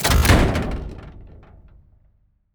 RELOAD2.wav